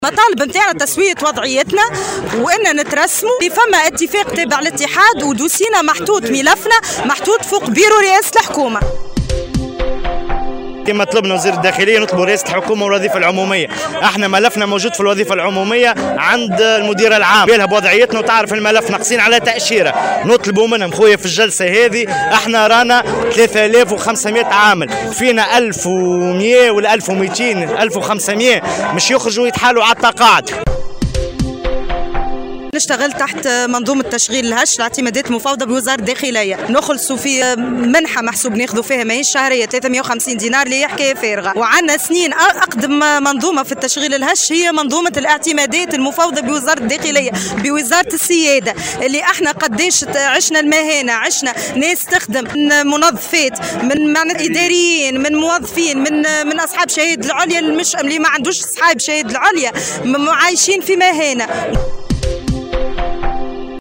نفذ صباح اليوم الاثنين، أعوان الاعتمادات المفوضة بوزارة الداخلية وقفة احتجاجية بساحة الحكومة بالقصبة. وطالبوا المحتجين بتسوية وضعياتهم المهنية “الهشة” وترسيمهم، كما ناشدوا وزير الداخلية بالتدخل العاجل و إنصافهم والتعهّد بملفاتهم.